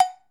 Cowbell_OS_5
Bell Cartoon Cow Cowbell Ding Dong H4n Ring sound effect free sound royalty free Movies & TV